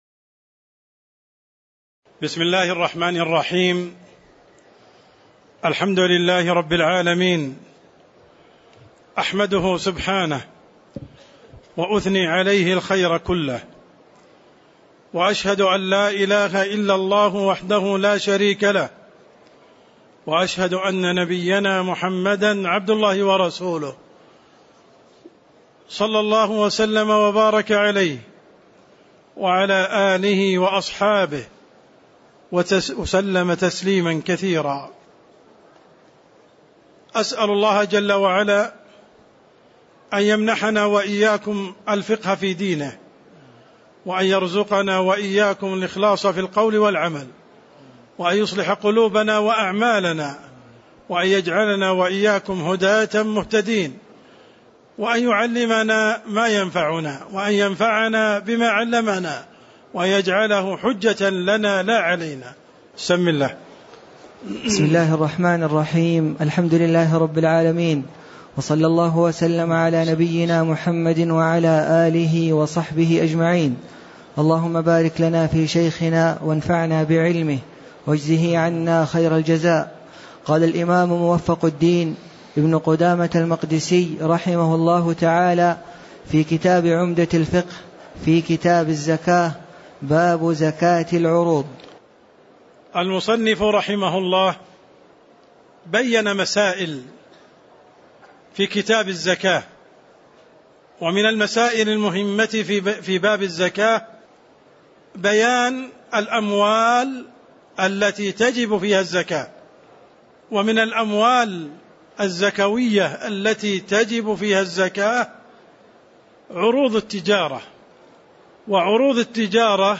تاريخ النشر ٢٨ محرم ١٤٣٩ هـ المكان: المسجد النبوي الشيخ: عبدالرحمن السند عبدالرحمن السند باب زكاة العروض (04)